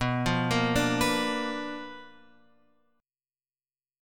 B Minor Major 7th Flat 5th